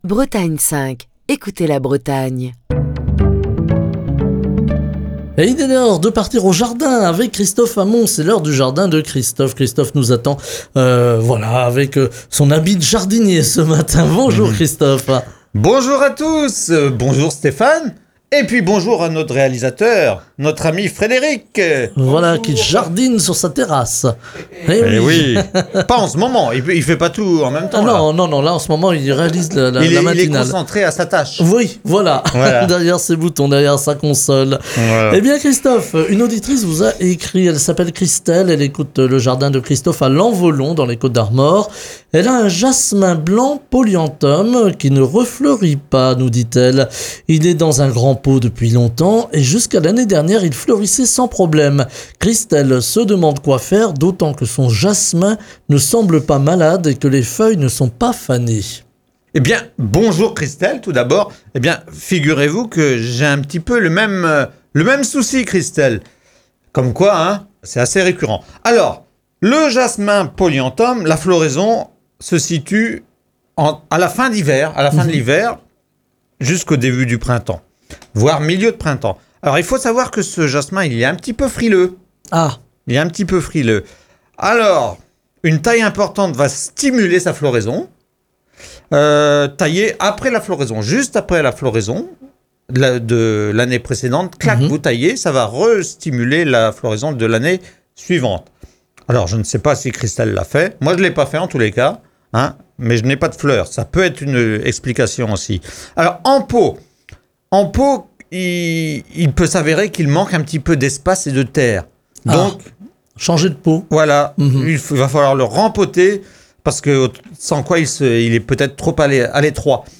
Émission du 15 mai 2024.